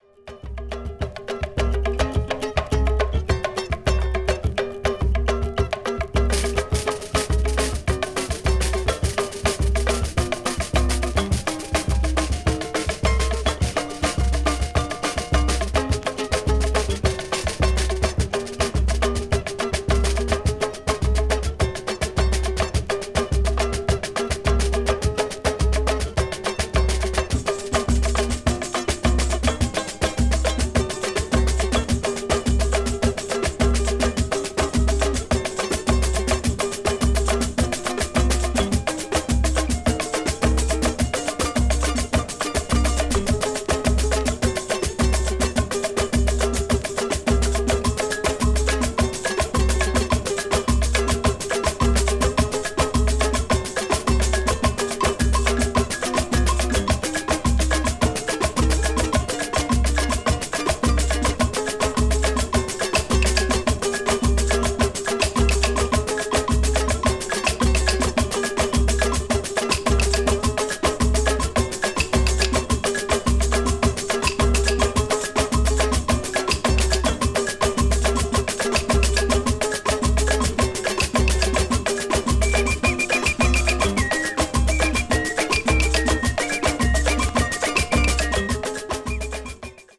batucada   brazil   ethnic music   samba   world music